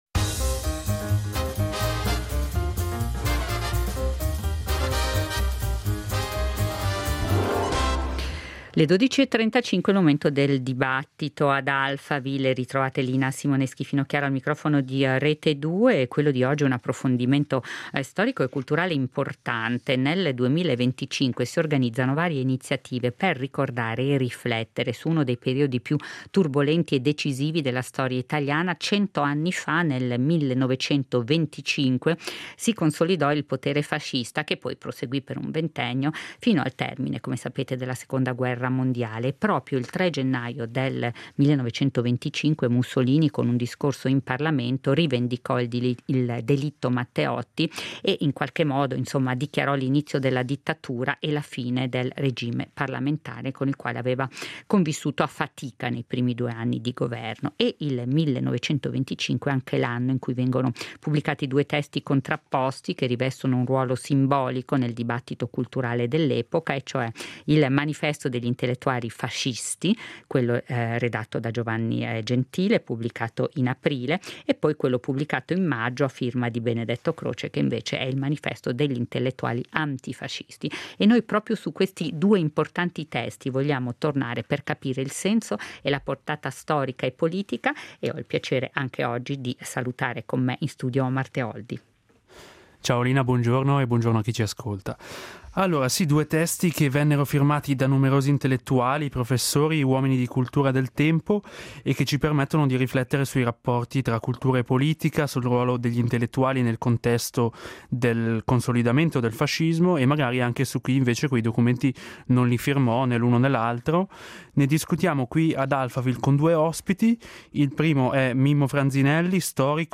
Ad Alphaville abbiamo intervistato